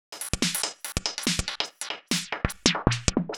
Index of /musicradar/uk-garage-samples/142bpm Lines n Loops/Beats
GA_BeatAFilter142-07.wav